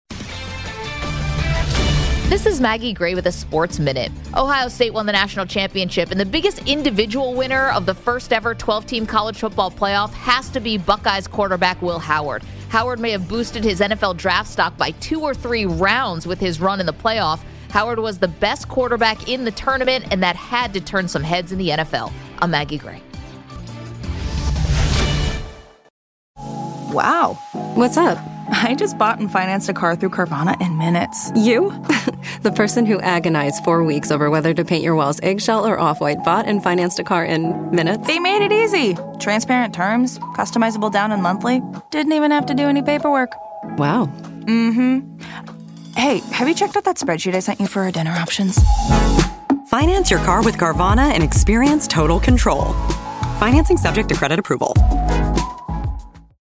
Hourly Commentaries between 6am-7pm by Infinity Sports Network talent